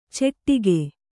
♪ ceṭṭi